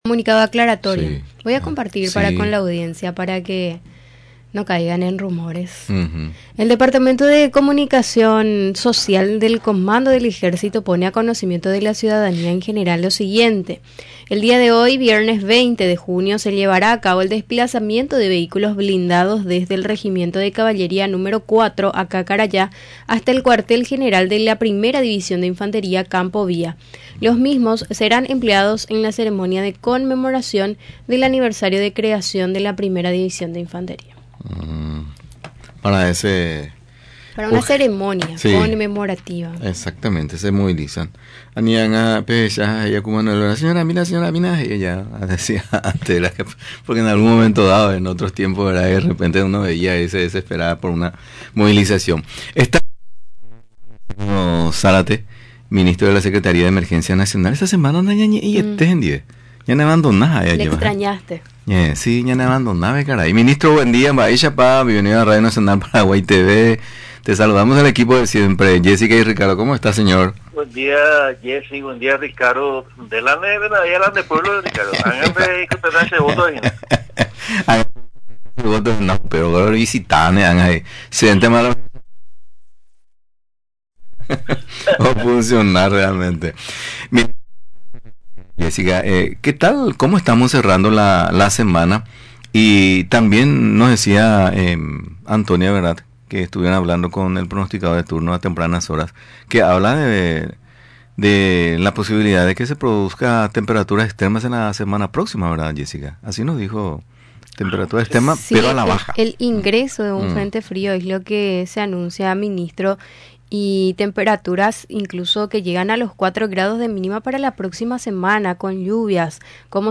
Durante la entrevista en Radio Nacional del Paraguay, resaltó que ésta tarea desarrollada por los funcionarios de la institución a su cargo, beneficiaron a unas 35.000 familias de ambos departamentos del país.